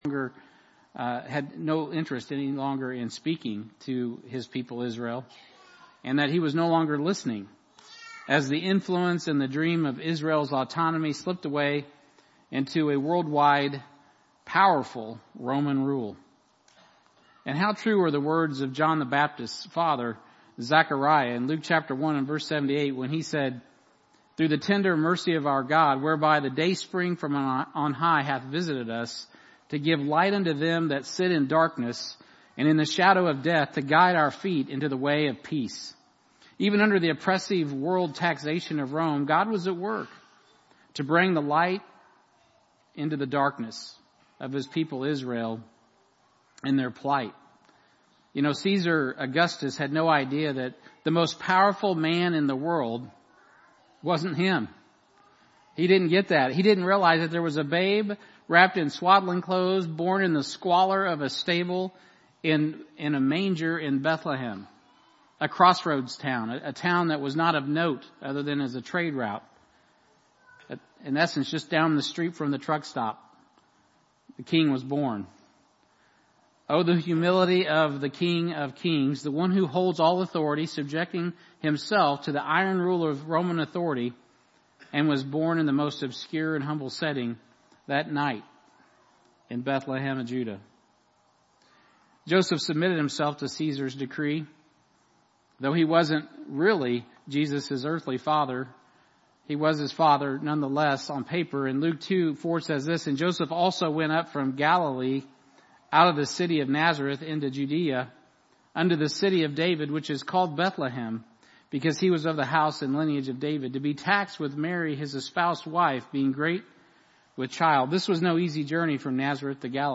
Christmas Eve Candlelight Service